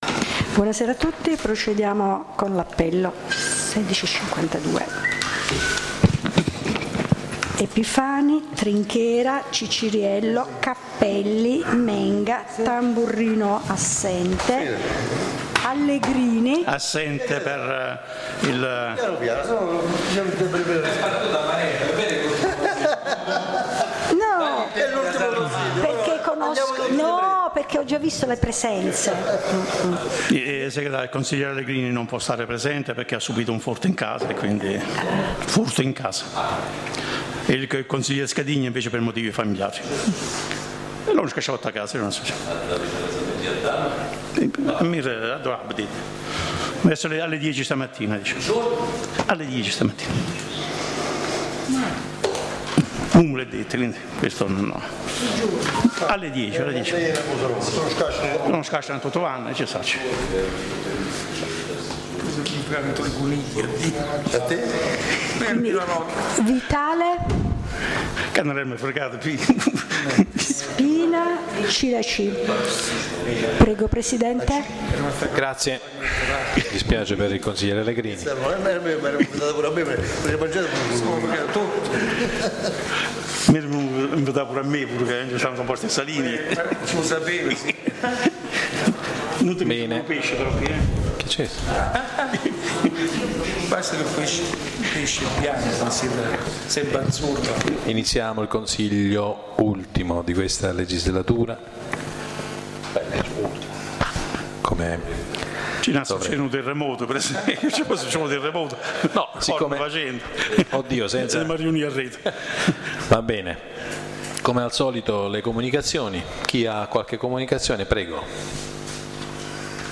La registrazione audio del Consiglio Comunale di San Michele Salentino del 26/05/2017
La registrazione audio del Consiglio Comunale di San Michele Salentino del 26/05/2017 (l’ultimo dell’amministrazione Epifani).